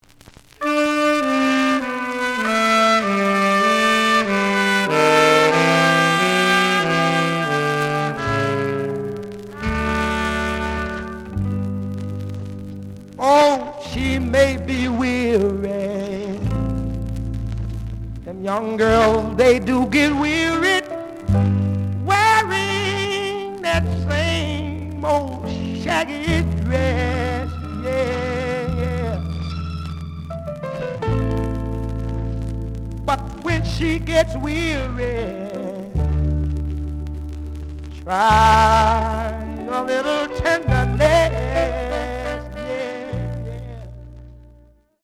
The audio sample is recorded from the actual item.
●Genre: Soul, 60's Soul
Slight noise on both sides.)